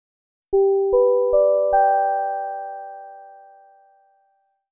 chimeup.wav